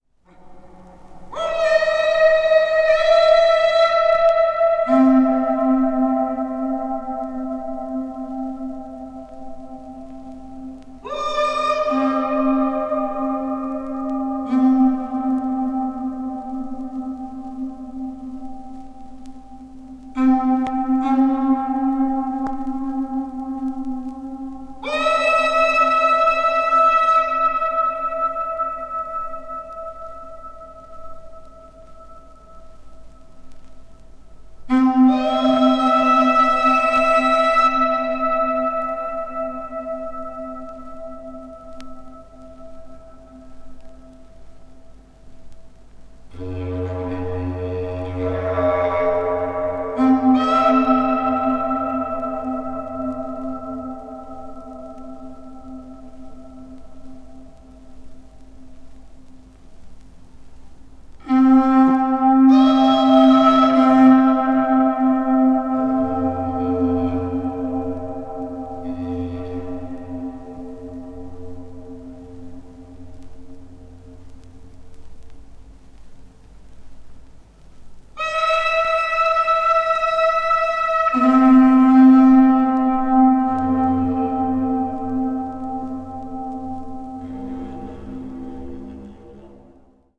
12世紀に建造されたフランス・アヴィニョンにある教皇宮殿で録音されたもの。
avant-garde   experimental   free improvisation   meditation